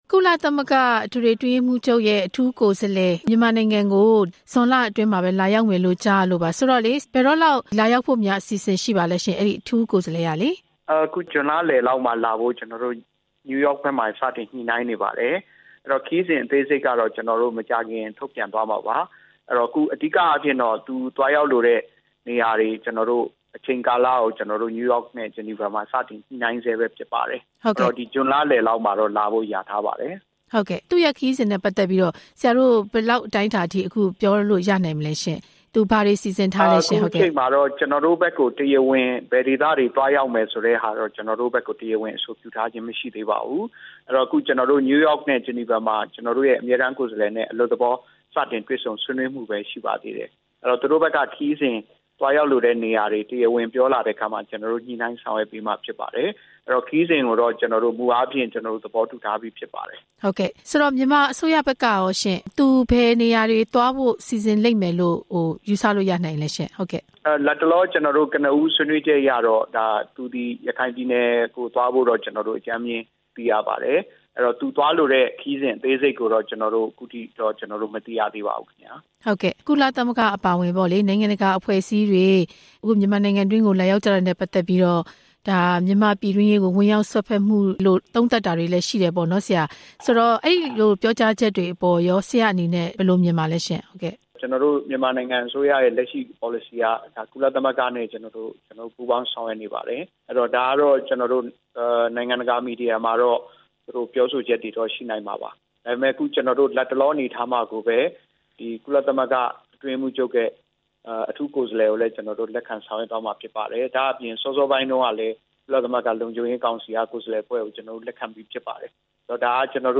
ကုလအထူးကိုယ်စားလှယ် ခရီးစဉ်အကြောင်း ဆက်သွယ်မေးမြန်းချက်